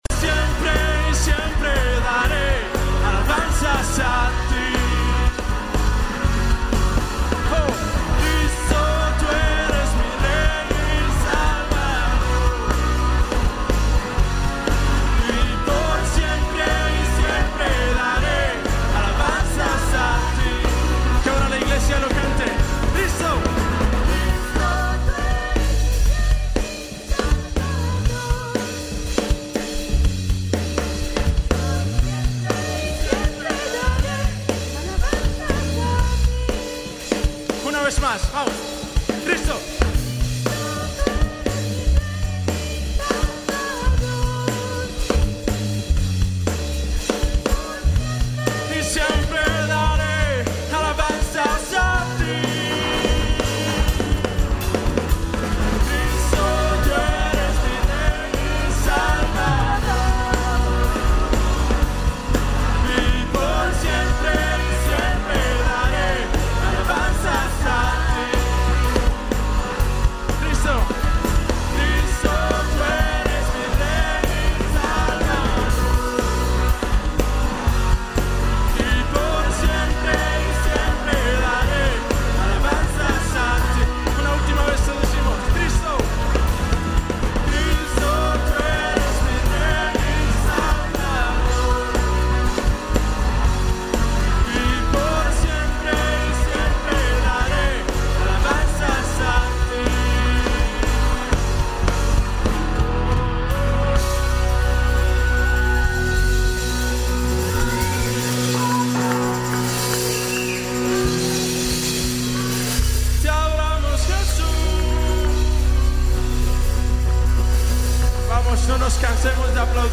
Alabanza febrero 8